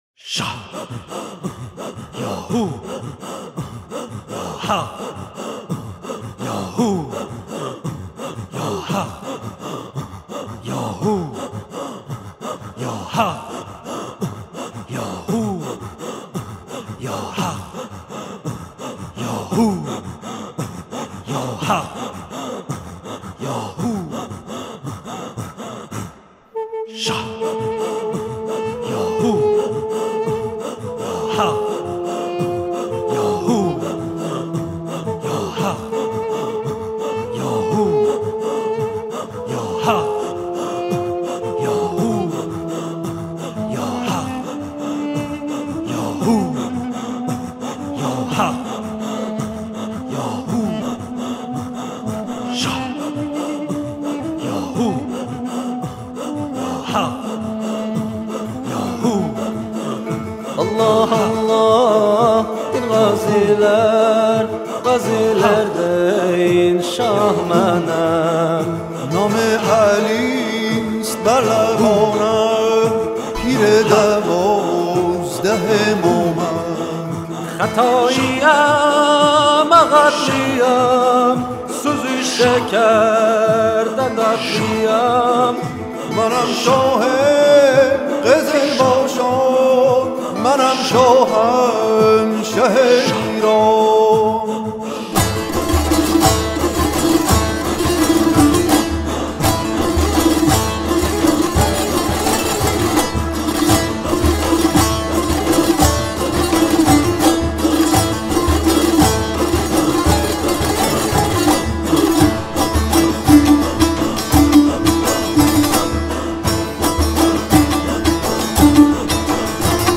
یک آهنگِ نوینِ حماسی(پهلوانی) با درون مایه های تاریخی